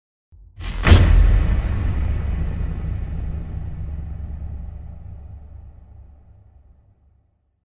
Звуки Impact
Слушайте онлайн и скачивайте бесплатно качественные ударные эффекты, которые идеально подойдут для монтажа видео, создания игр, рекламных роликов и постов в соцсетях.